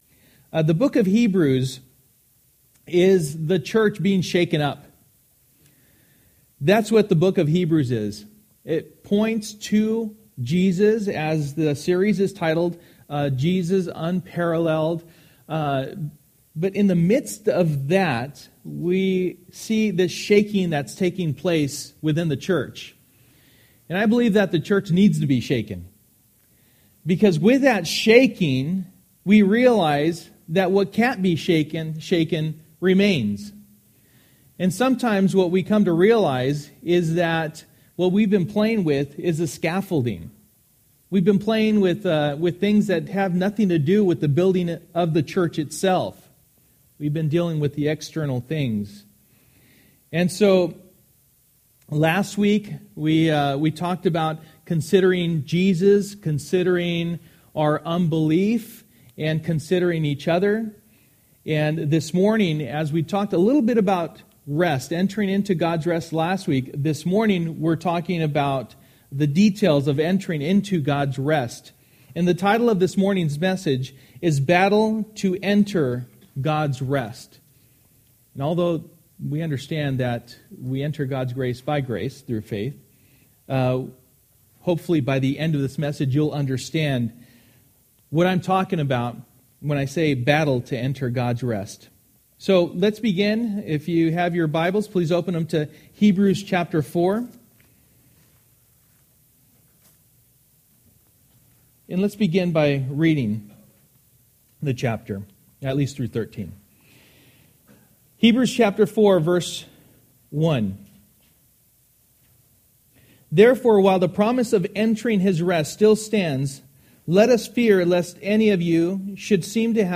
Jesus Unparralleled Passage: Hebrews 4:1-13 Service: Sunday Morning %todo_render% « True Faith Leads to Action Enter Freely